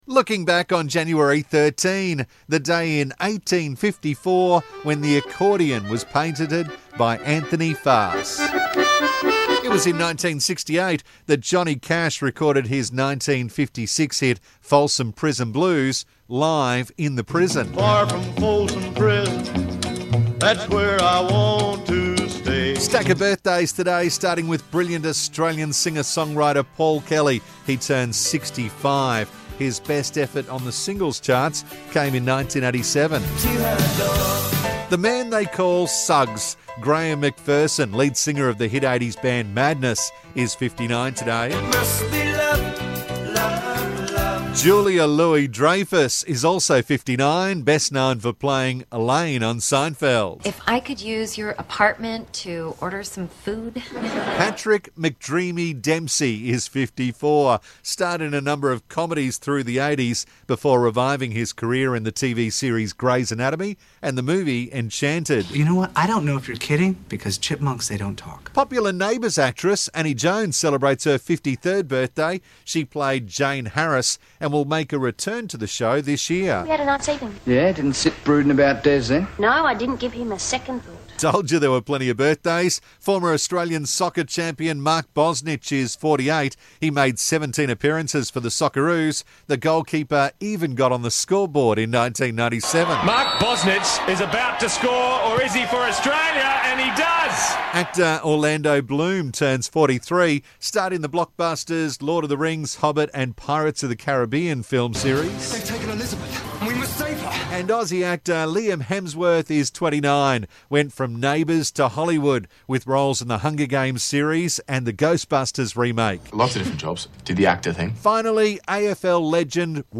Accordion.